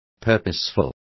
Complete with pronunciation of the translation of purposeful.